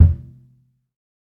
kits/Cardiak/Kicks/TC3Kick11.wav at main
TC3Kick11.wav